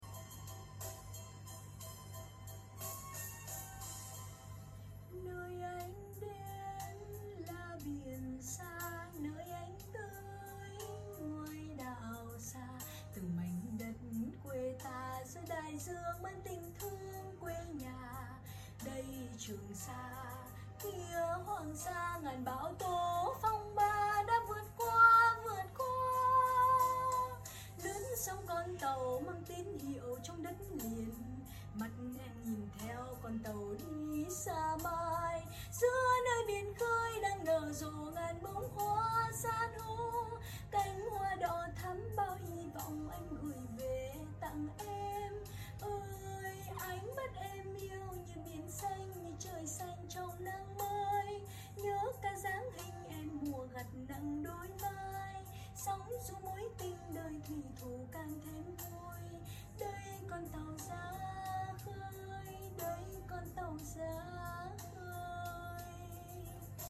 những bản nhạc quê hương trữ tình